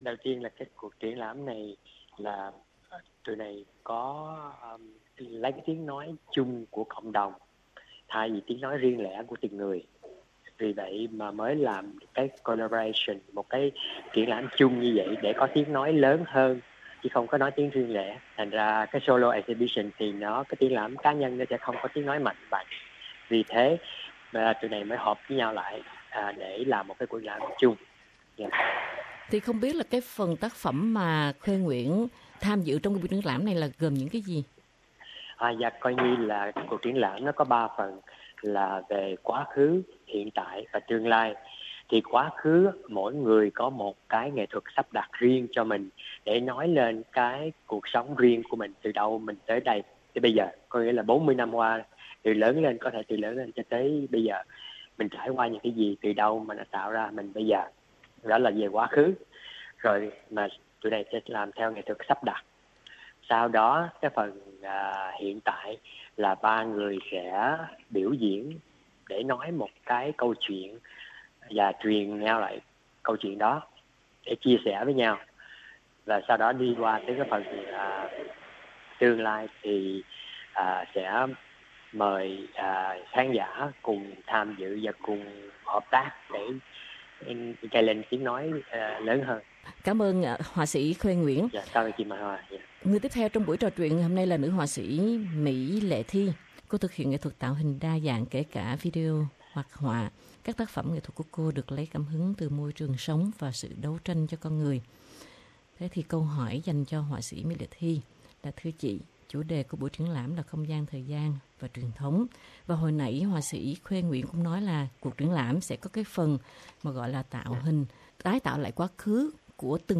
có cuộc trò chuyện với ba nghệ sỹ